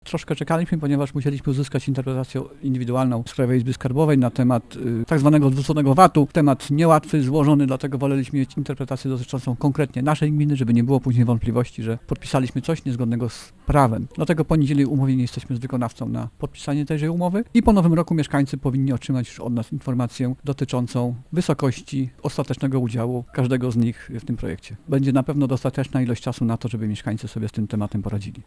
Nie będzie to jednak miało wpływu na termin zakupu i montażu OZE - zapowiada wójt gminy Gościeradów Mariusz Szczepanik: